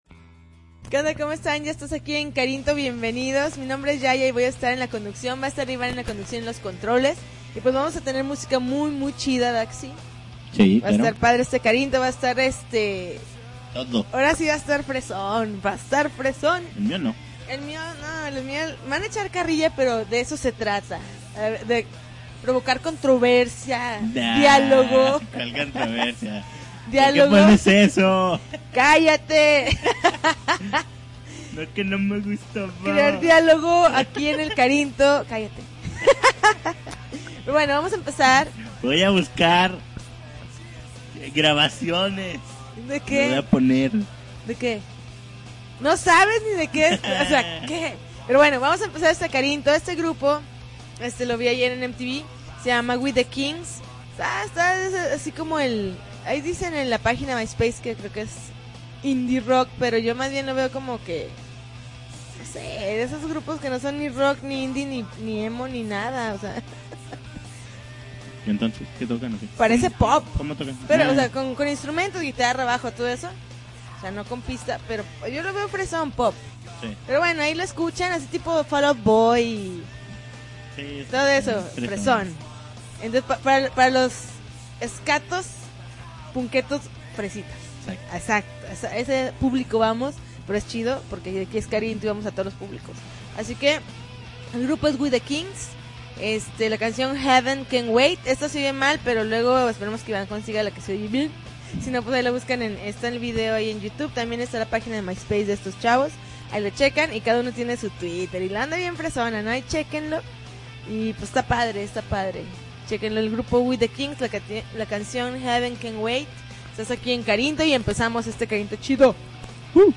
February 7, 2010Podcast, Punk Rock Alternativo